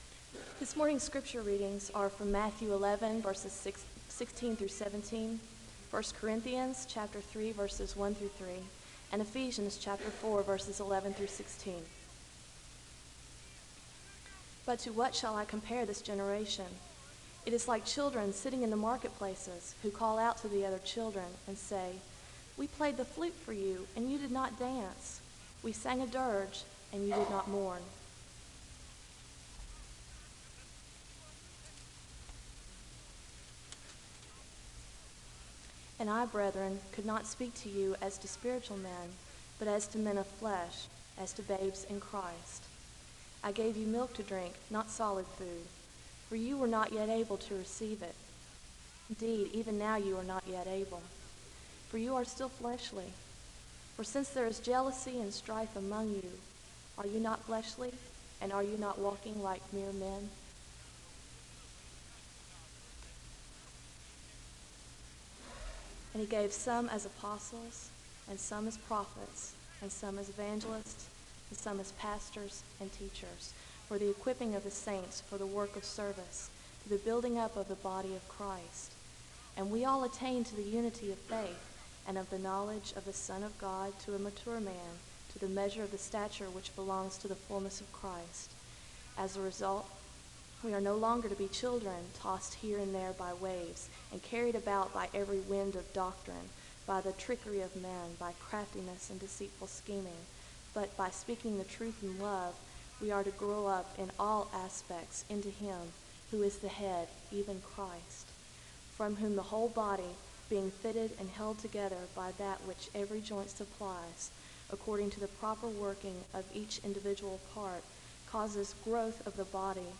Preaching
The service begins with Scripture readings from Matthew, I Corinthians, and Ephesians (0:00-2:07). There is a moment of prayer (2:08-3:47).